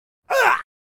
gag2.ogg